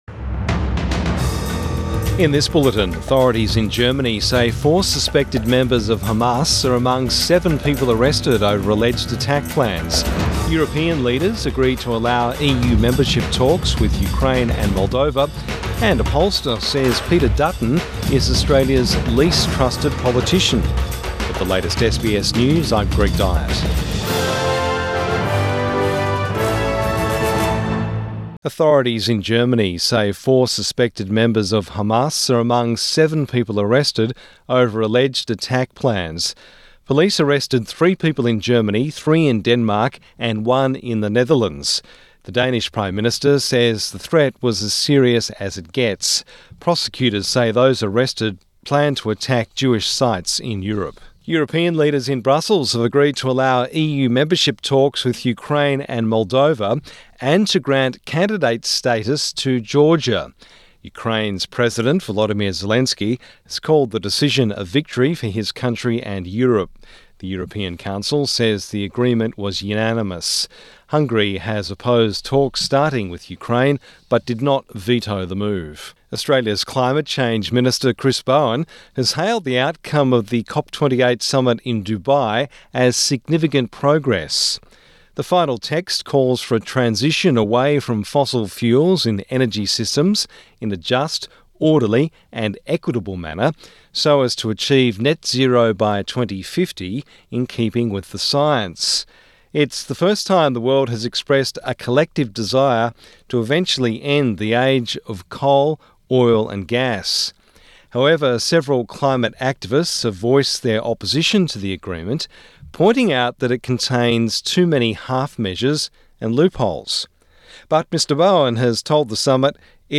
Morning News Bulletin 15 December 2023